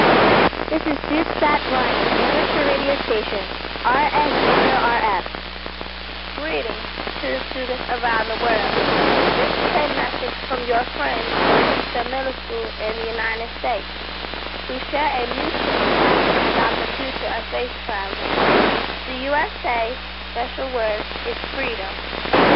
I received 14 sstv pictures and 90 audio clips from the space suit as it orbited the globe every 90 minutes for two weeks.
Special greetings in German and Spanish, Russian, French, Japanese, and English had been pre-recorded by these students from different countries.
The sequence of the transmission was a voice ID (5 seconds), an international voice message, telemetry data or a SSTV Image (15-45 seconds), and then a 30 second pause.